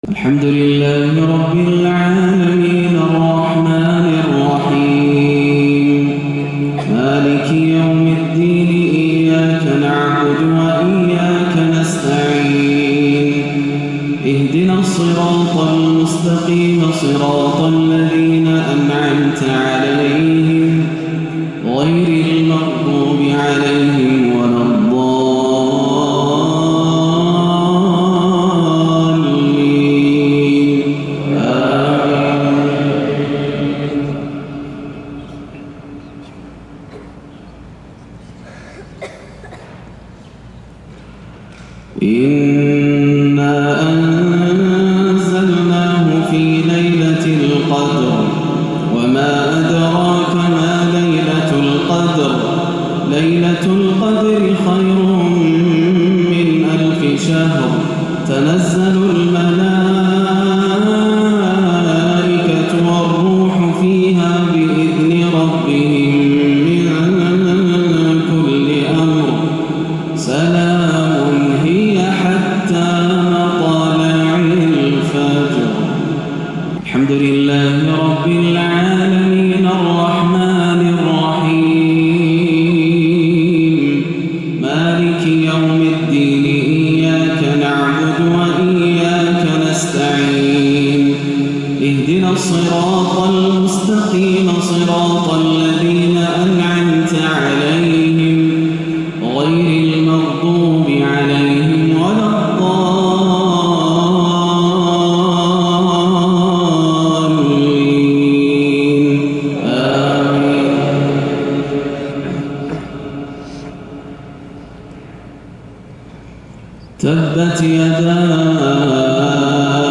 مغرب السبت 30 رجب 1437هـ سورتي القدر و المسد > عام 1437 > الفروض - تلاوات ياسر الدوسري